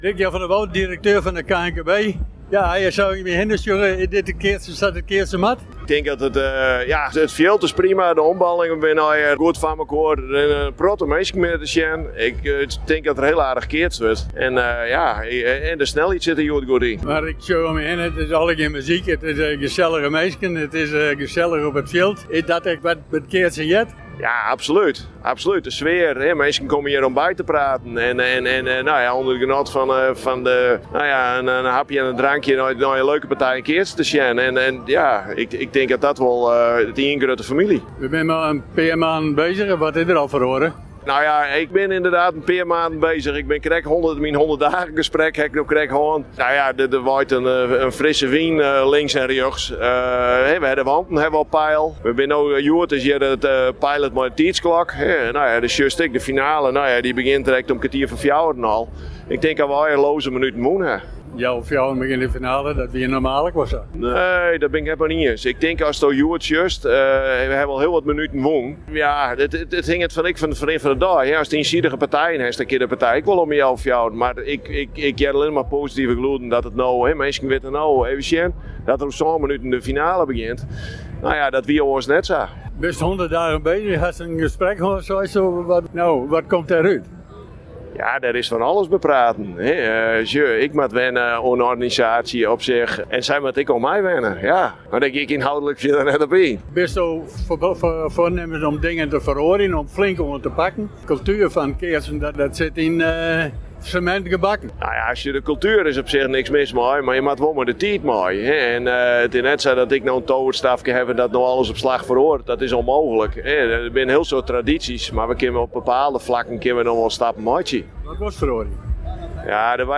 Verder een gesprek